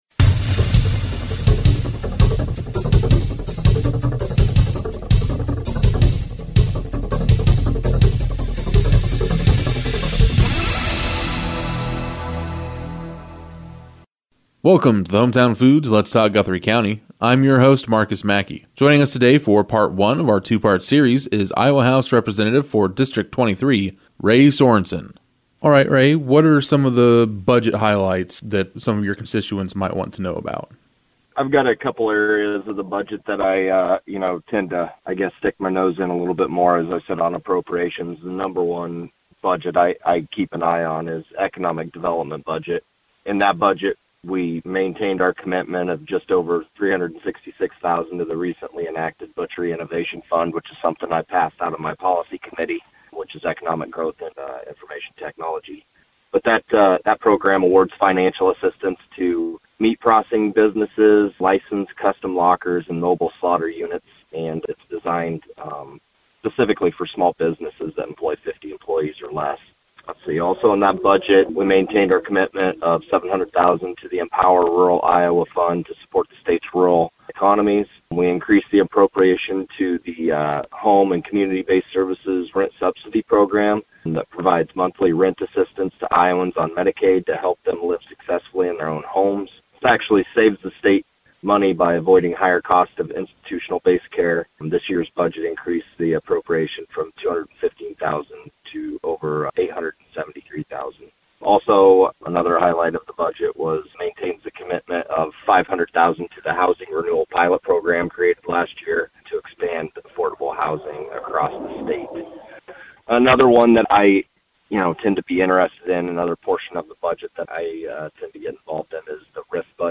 We discuss with Iowa House District 23 Representative Ray Sorensen.